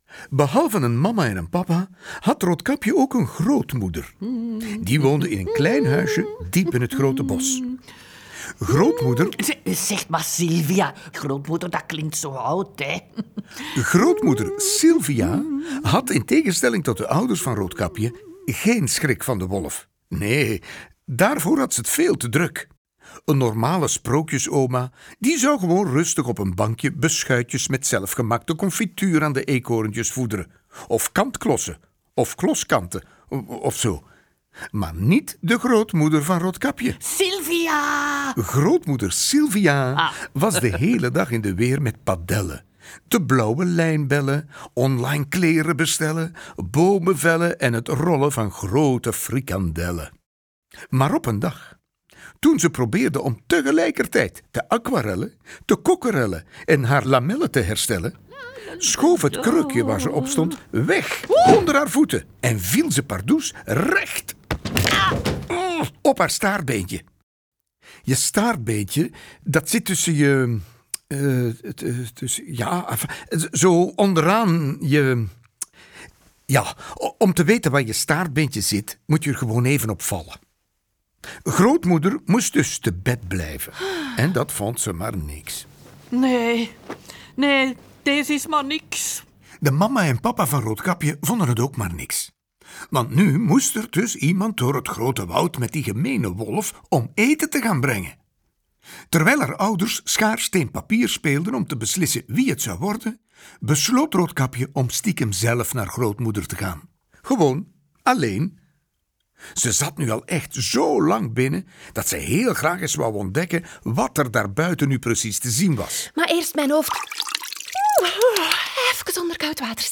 Je kan deze verhalen zelf lezen of voorlezen.